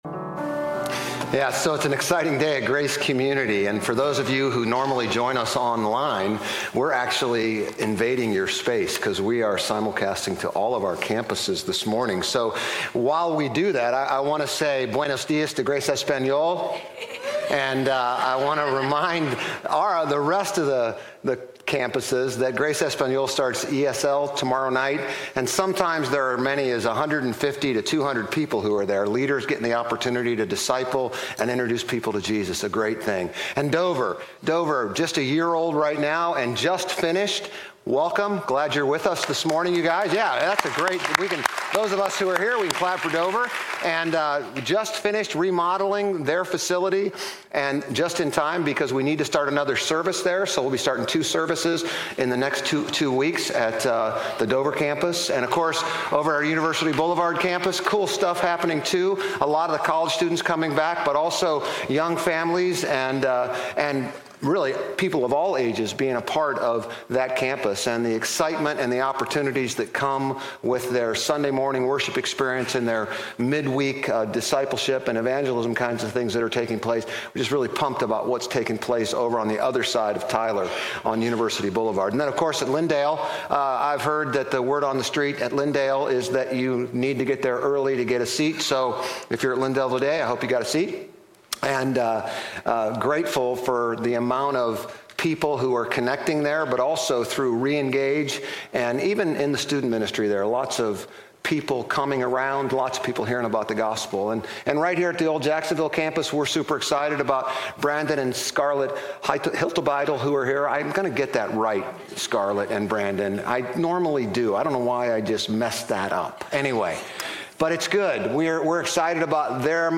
Grace Community Church Dover Campus Sermons 9_7 Dover Campus Sep 08 2025 | 00:30:06 1x 00:00 / 00:30:06 Subscribe Share RSS Feed Share Link Embed